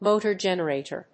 アクセントmótor gènerator